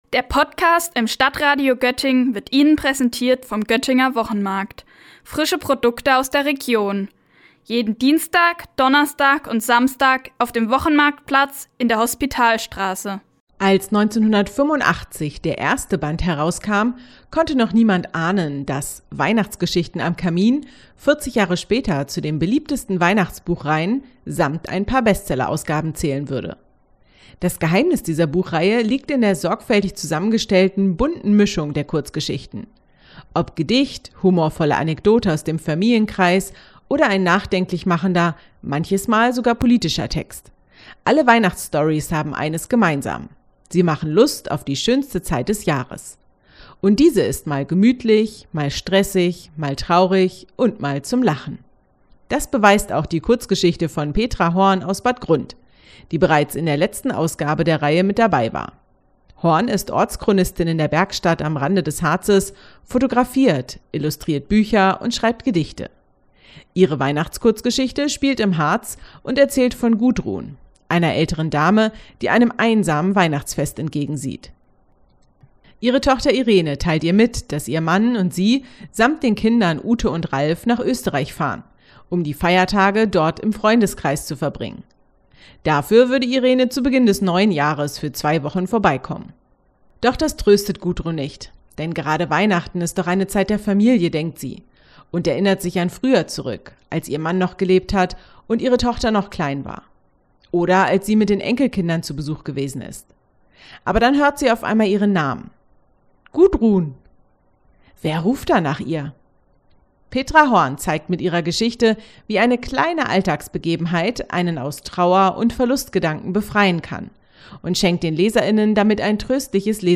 Beiträge > Von Northeim bis Bad Grund - Buchrezension: „Weihnachtsgeschichten am Kamin 40” - StadtRadio Göttingen